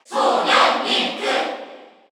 Category: Crowd cheers (SSBU) You cannot overwrite this file.
Young_Link_Cheer_Korean_SSBU.ogg